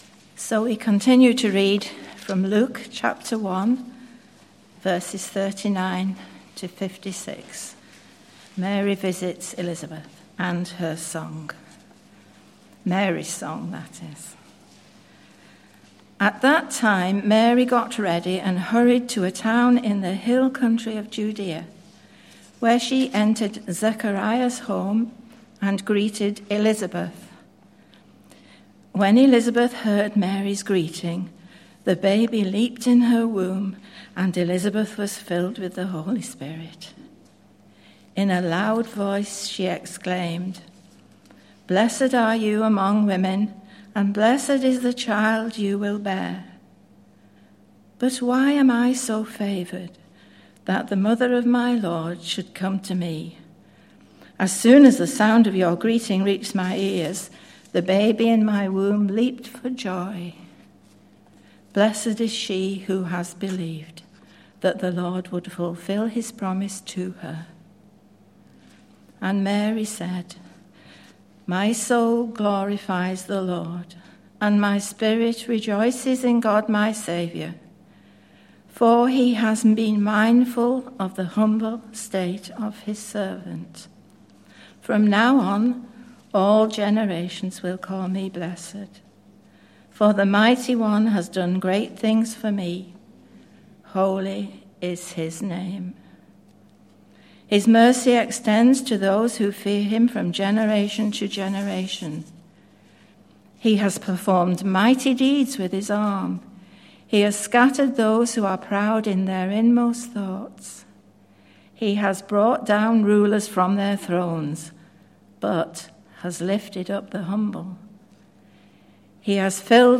Service Type: Morning Service 11:15